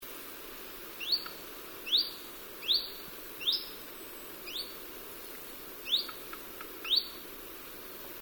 Chiffchaff calls
All from Northwestern Estonia, 10-11 September 2005.